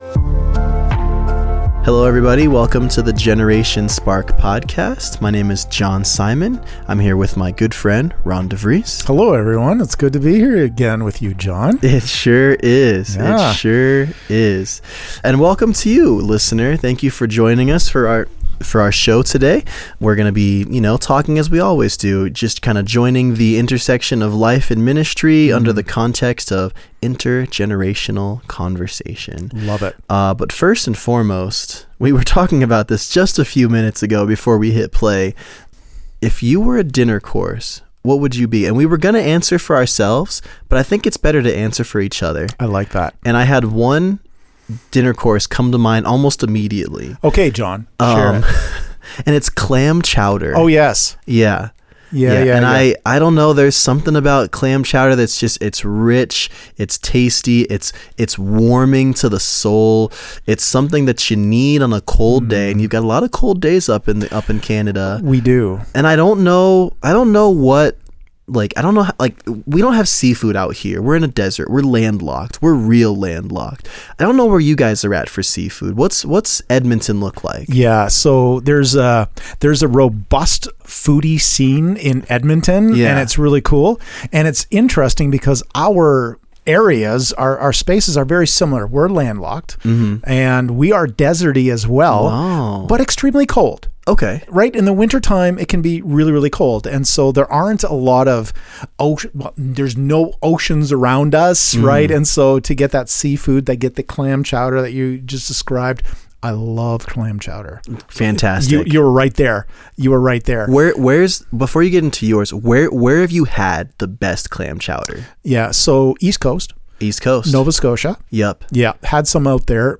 Connecting life, theory, practice and ministry with an intergenerational conversation.&nbsp